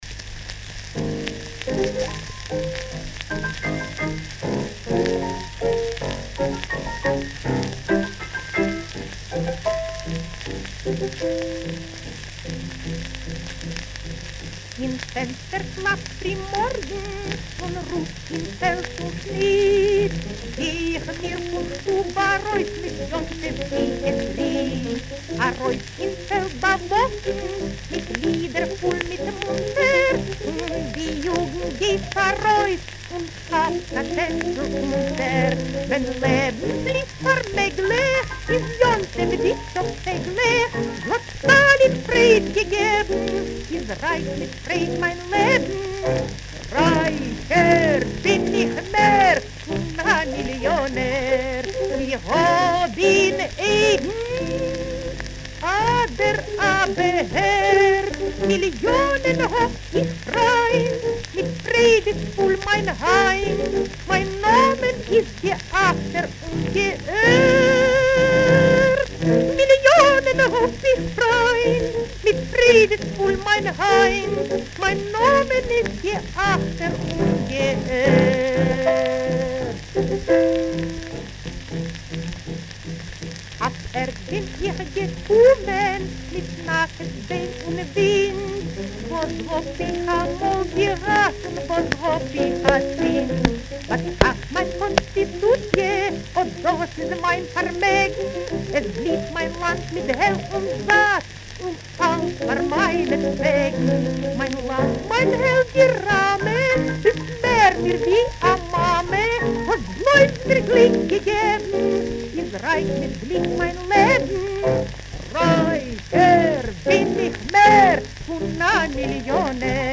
Народно-политизированная песня советских евреев.
рояль) Исполнение 1940г.